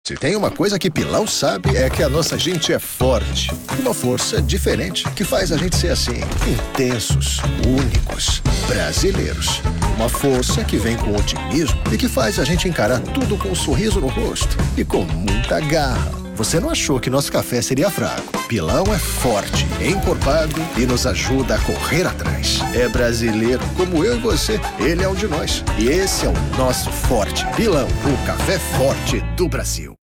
Natural, Friendly, Reliable, Corporate, Accessible
Commercial